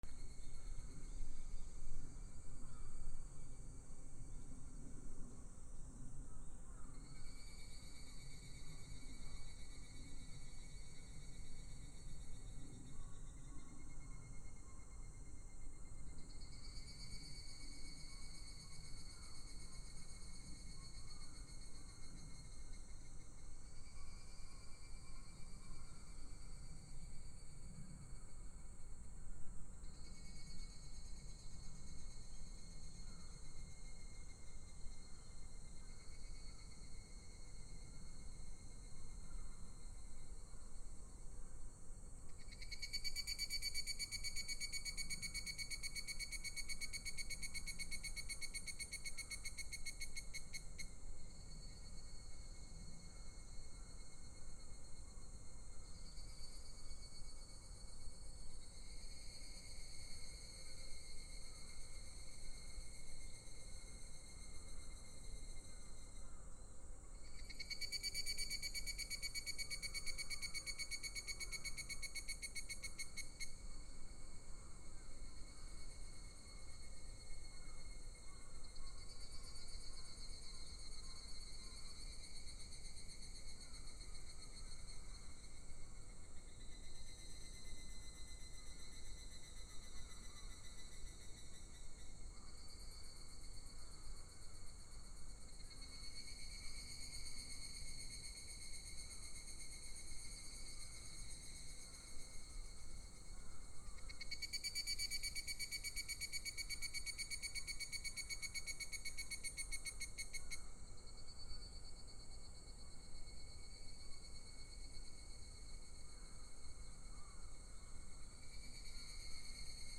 初夏 セミ
/ B｜環境音(自然) / B-25 ｜セミの鳴き声 / セミの鳴き声_70_初夏